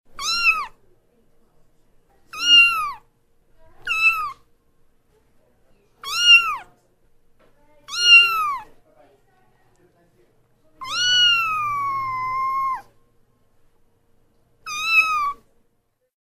Котёнок Мяукает